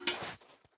fill_powder_snow1.ogg